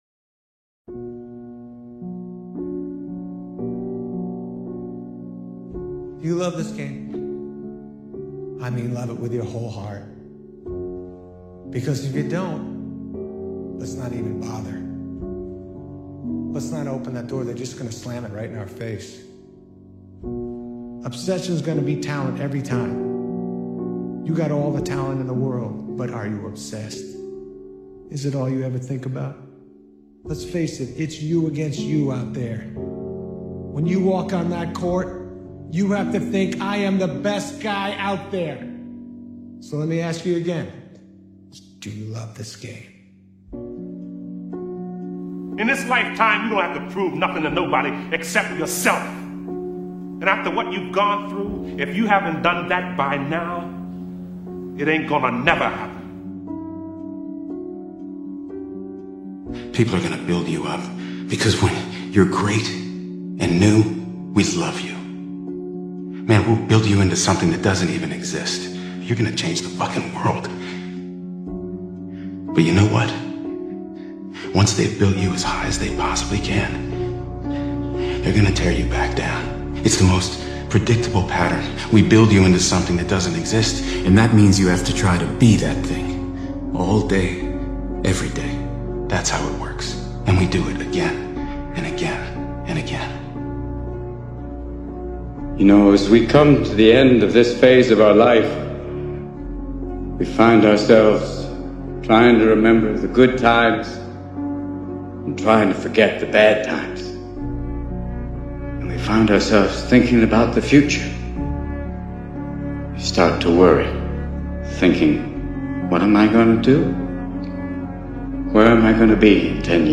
Change Your Mindset | Best Motivational Speech